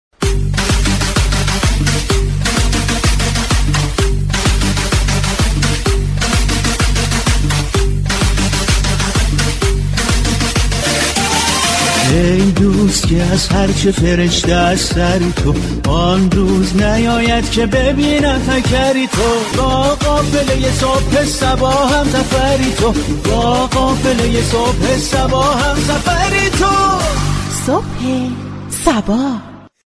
رادیو صبا در اولین روز هفته شنبه یازدهم دی برنامه زنده« صبح صبا» را با موضوع تشكرو قدردانی راهی آنتن می كند.
برنامه «صبح صبا » با پخش آتیم های طنز، اجرای نمایش های زنده اخبار به این موضوع می پزازد و در كنار آن آخرین اخبار روز را بانگاهی طنز به اطلاع مخاطبان می رساند.